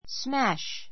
smash smǽʃ ス マ シュ